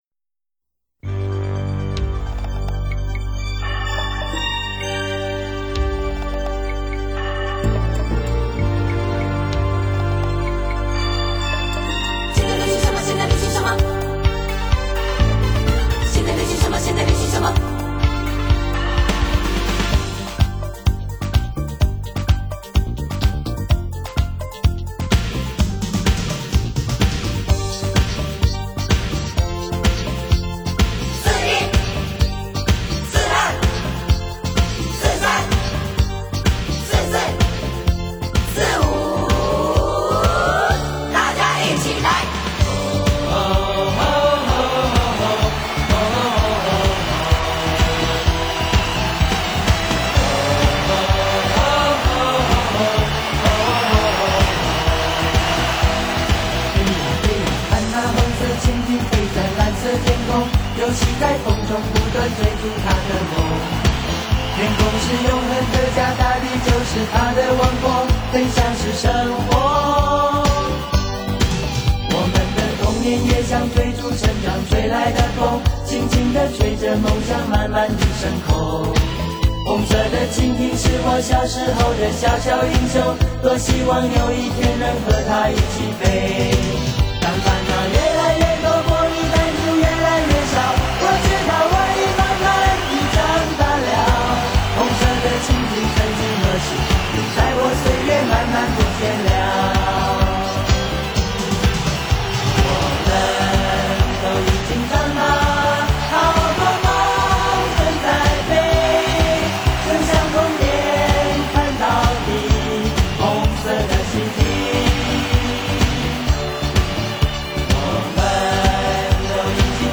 45就是 采45轉快轉的方式演唱串聯當紅歌曲的組曲，記錄著70.80年代臺灣流行樂史.
(wma/32k - 歌曲太長 所以用超低音質當試聽 請見諒!!!)